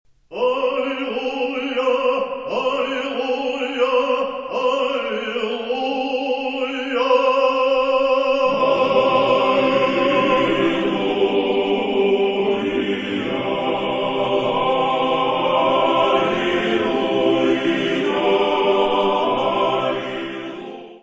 Descrição Física:  1 Disco (CD) (55 min.) : stereo; 12 cm
Área:  Música Clássica